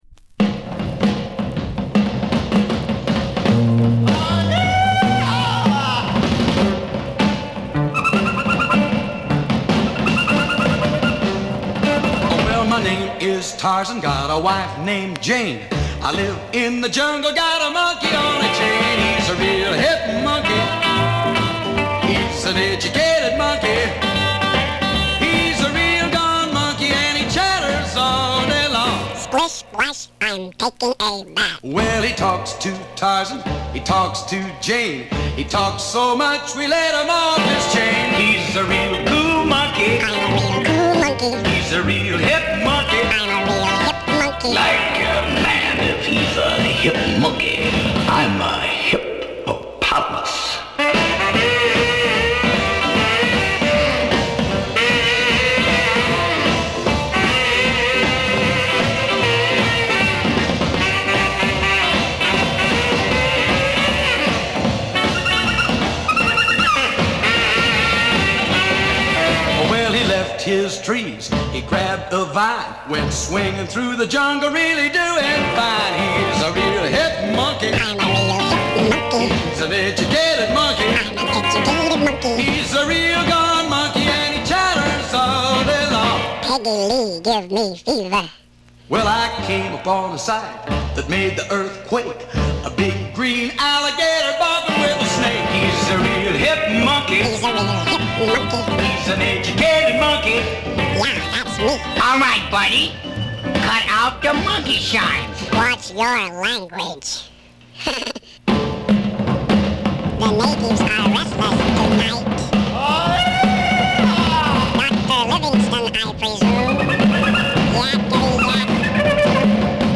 先物買い的な良品だ[Comped] [Tittyshaker] [Exotica] [NEW]